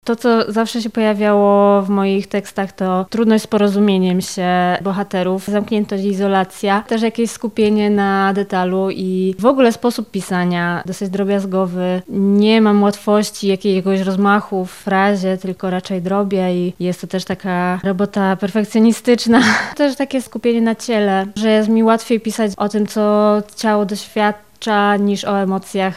Spotkanie odbyło się w Warsztatach Kultury w Lublinie.